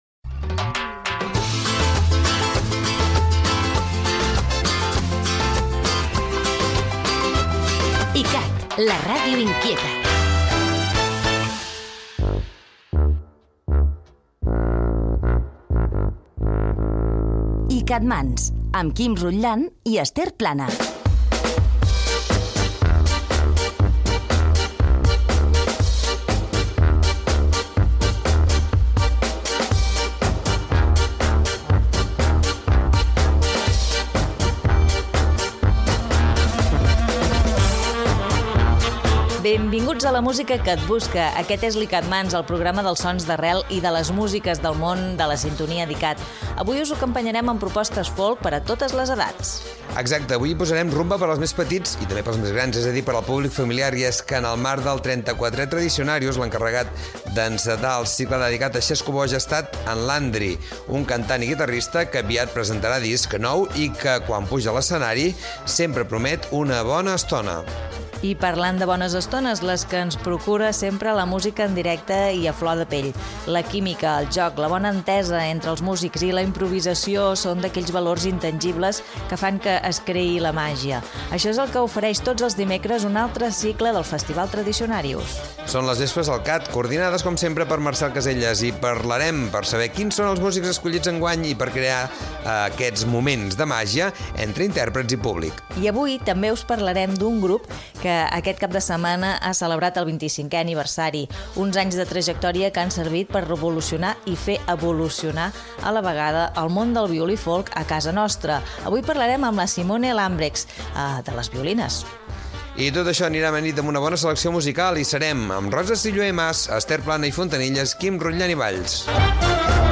Indicatiu de la ràdio, careta del programa, sumari de continguts, publicitat, tema musical
Cultura